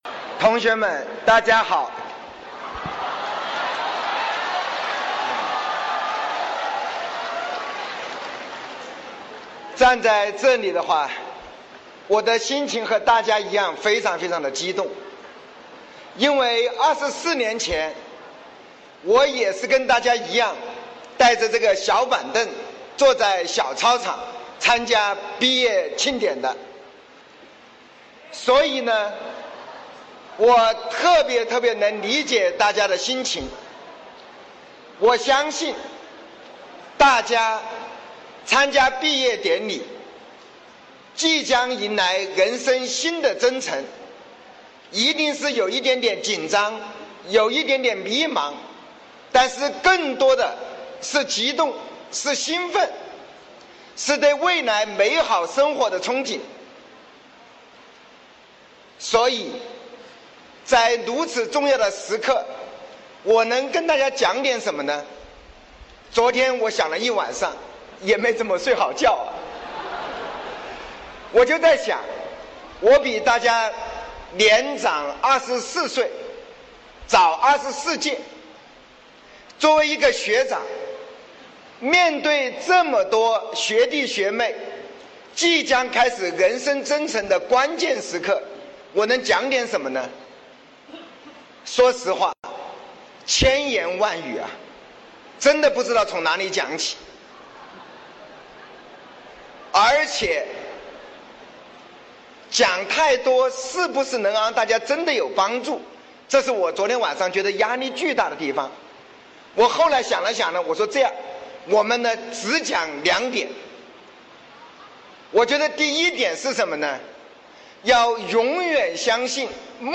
雷军2015年武汉大学毕业典礼演讲